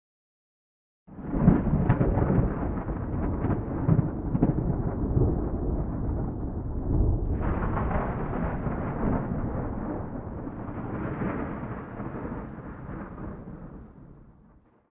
thunder-4.ogg